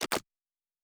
Fantasy Interface Sounds
UI Tight 07.wav